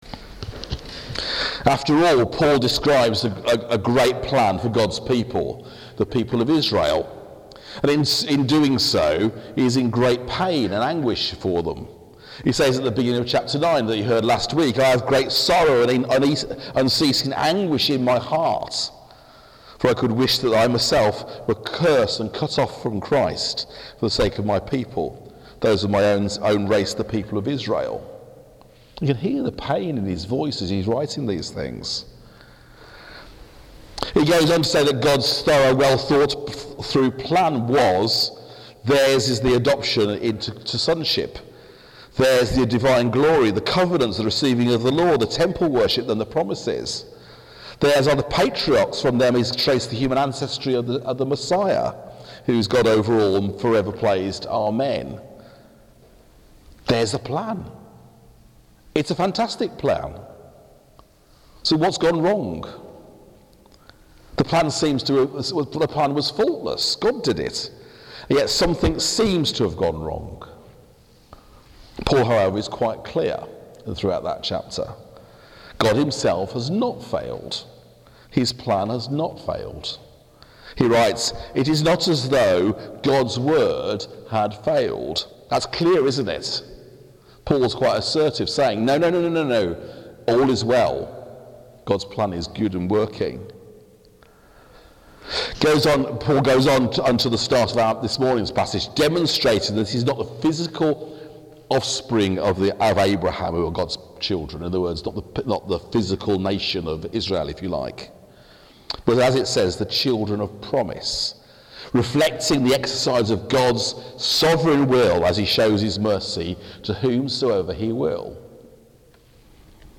Romans 9:30-10:13 Service Type: Morning Service « Matthew 5:10-12